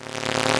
surge collection noise
Collect.mp3